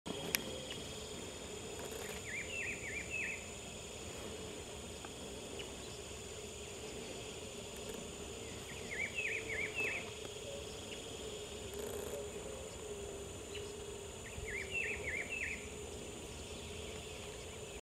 Rufous-browed Peppershrike (Cyclarhis gujanensis)
Life Stage: Adult
Condition: Wild
Certainty: Recorded vocal